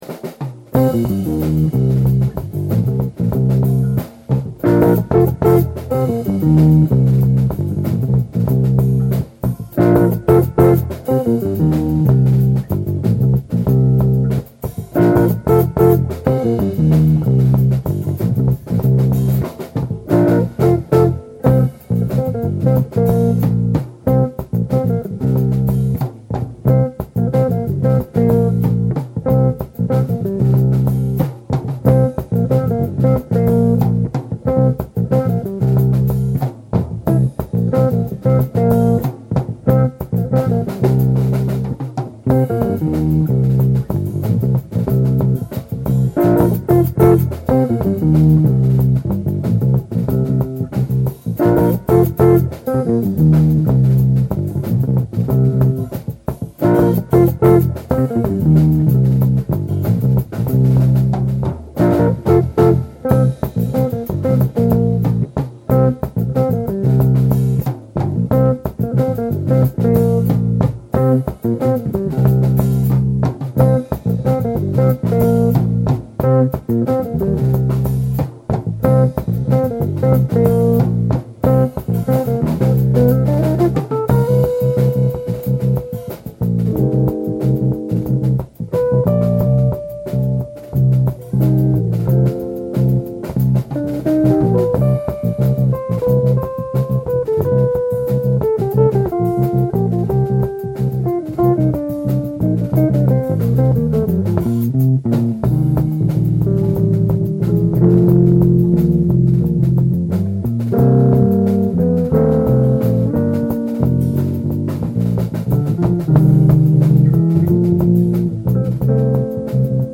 bass guitar
drums
sax
In 1998, the band added jazz influences to their music.